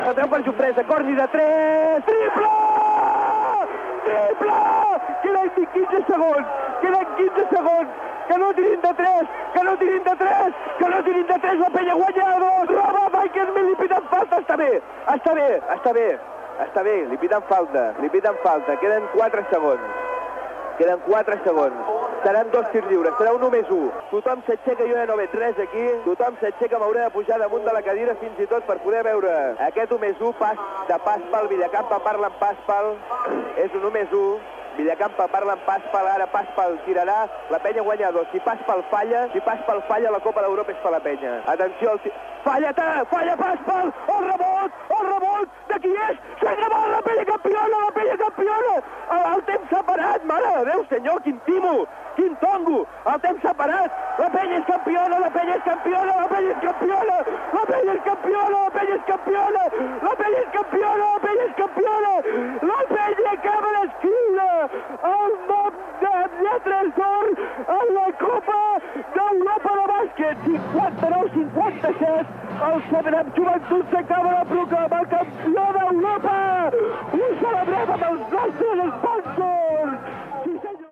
Narració dels últims segons de la final de la Copa d'Europa de Bàsquet masculí entre el 7up Joventut i l'Olympiacos, des del Yad Eliyahu de Tel Aviv (Israel)
Esportiu